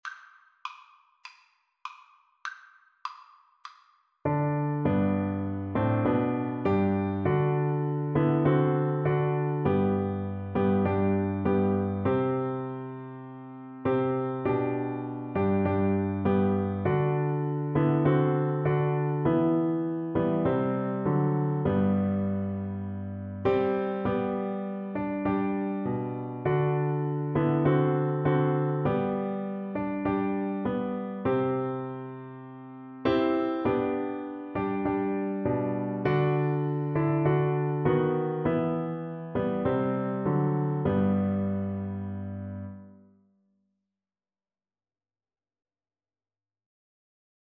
Soprano (Descant) Recorder version
4/4 (View more 4/4 Music)
Recorder  (View more Easy Recorder Music)
Traditional (View more Traditional Recorder Music)